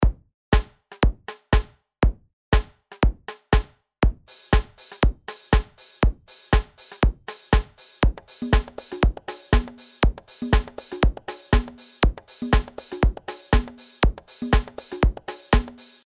Hier habe ich die Bassdrum knackiger gemacht und mit dem Modulationsrad den Start-Parameter moduliert. Auf der 1 und 3 sind die Schläge betont, auf der 2 und 4 eines jeden Taktes etwas zurückgenommen.